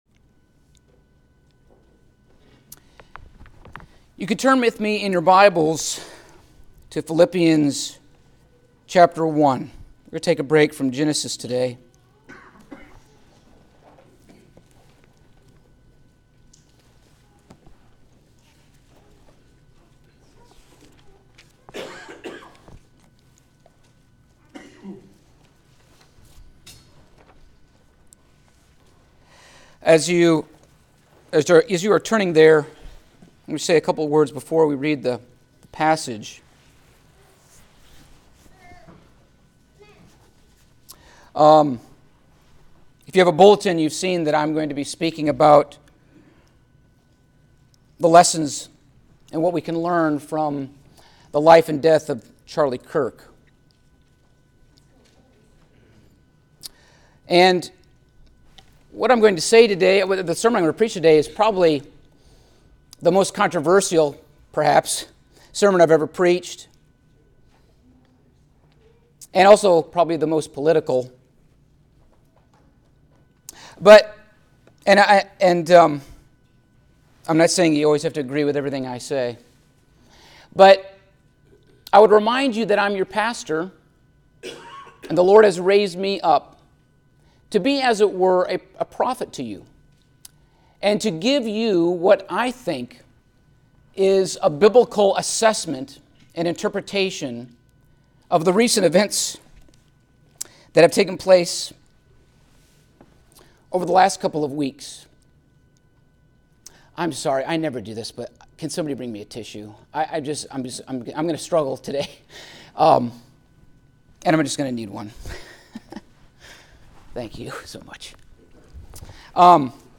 Passage: Philippians 1:12-20 Service Type: Sunday Morning